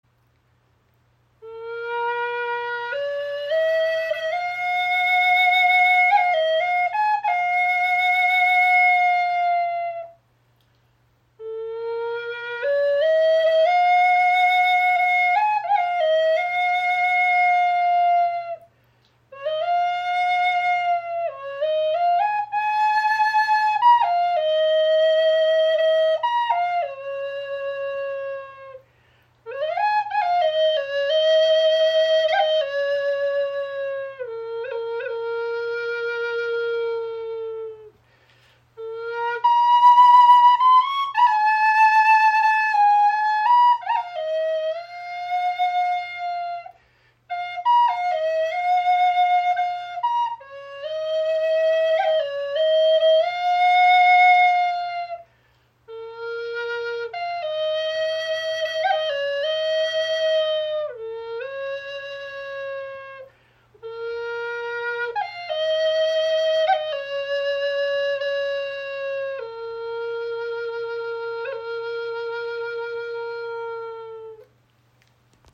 • Icon 46 cm Gebetsflöte aus naturbelassenem Teakholzast mit Rinde
• Icon Gestimmt in B (H) auf 432 Hz – lichtvoller, erhebender Klang
Diese handgeschnitzte Flöte aus einem naturbelassenen Teakholzast trägt mit ihrem lichtvollen Ton in B (H) die Kraft, Herz und Geist zu erheben und Dich mit der reinen Schwingung der Natur zu verbinden.